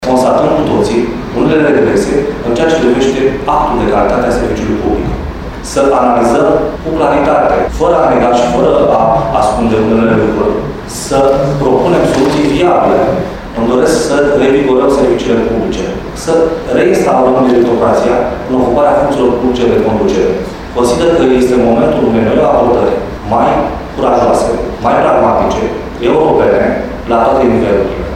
Sâmbătă s-a desfășurat, la Prefectura Suceava, ședința festivă de instalare în funcție a noului prefect ALEXANDRU MOLDOVAN.